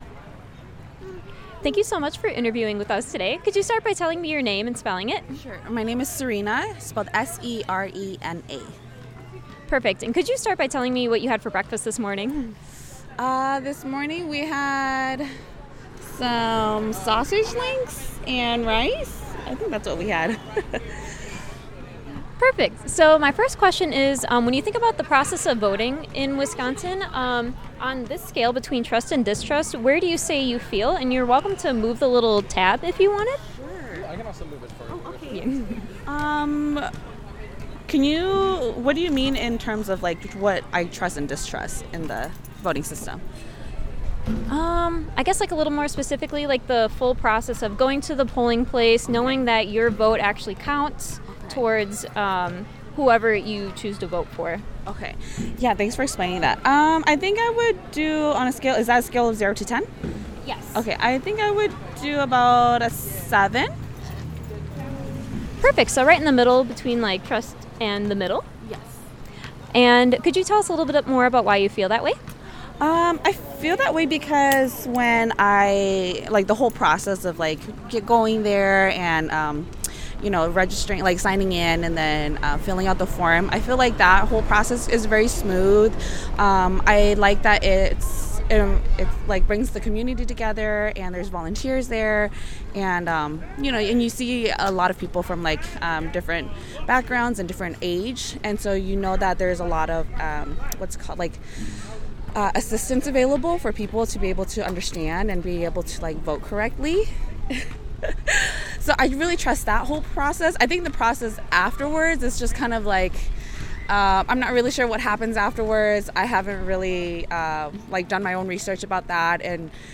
Rooted and Rising Block Party